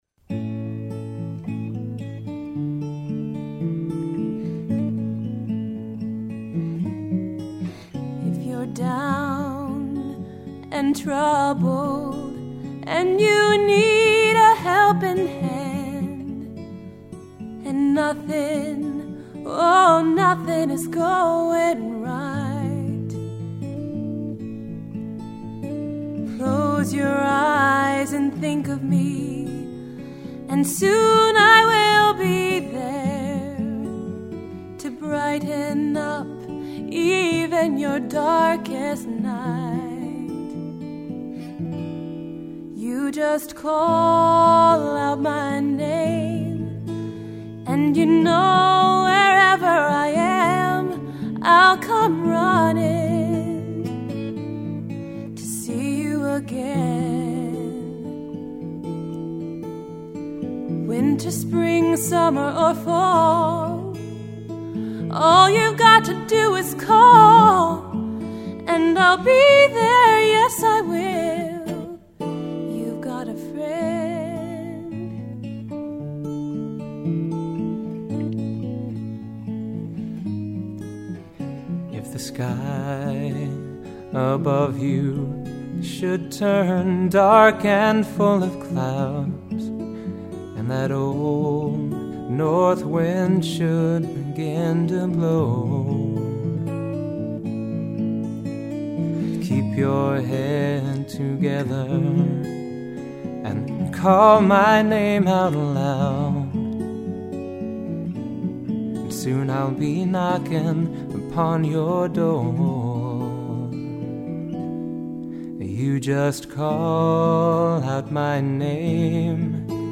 1) These are all first takes.